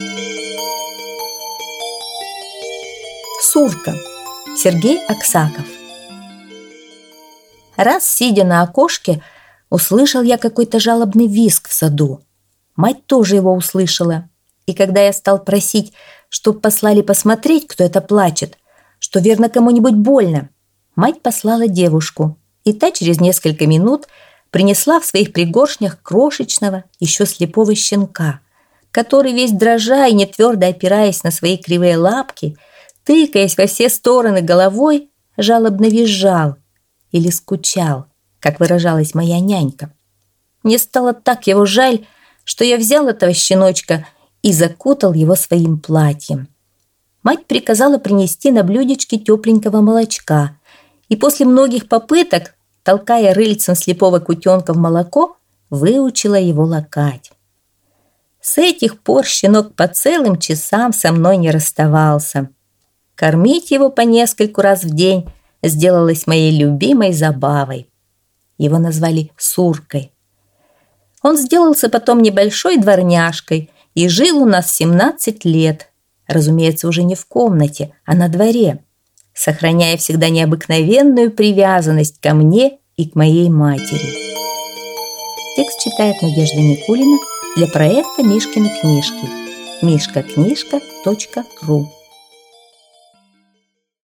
Аудиорассказ «Сурка»